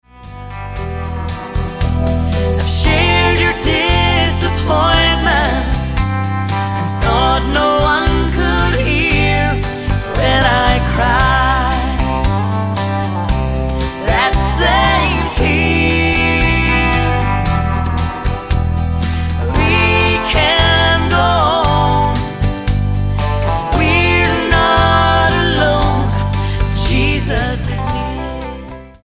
STYLE: Country